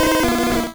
Cri de Salamèche dans Pokémon Rouge et Bleu.